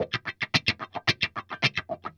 WAHWAHCHUNK1.wav